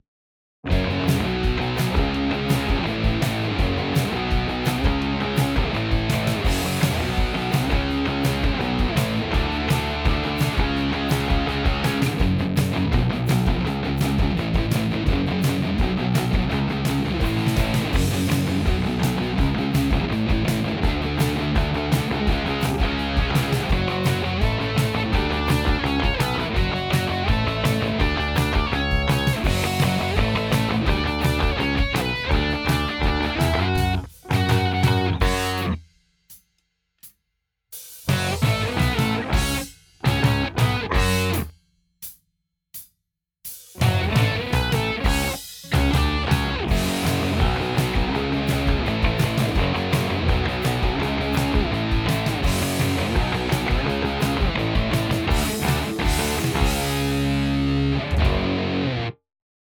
Rock Riffing
Pop Punk